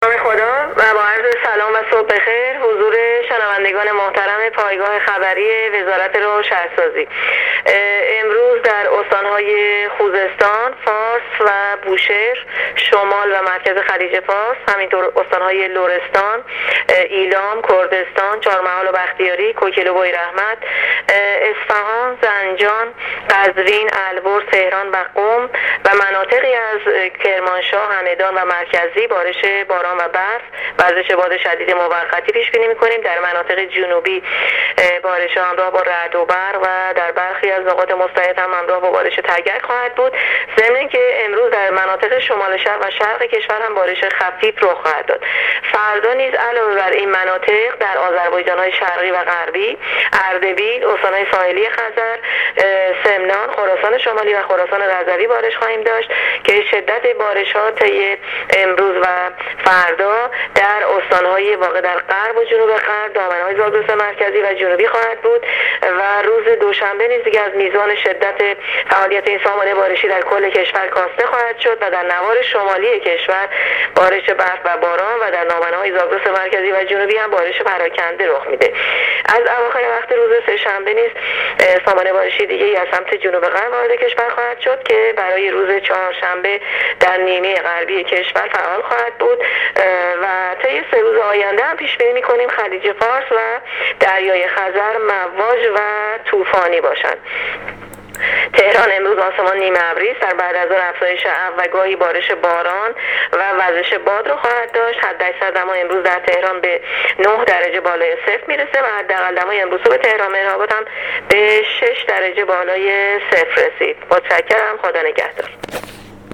کارشناس سازمان هواشناسی کشور در گفت‌وگو با رادیو اینترنتی وزارت راه و شهرسازی، آخرین وضعیت آب و هوای کشور را تشریح کرد
گزارش رادیو اینترنتی از آخرین وضعیت آب‌‌و‌‌‌هوای ۸ آذر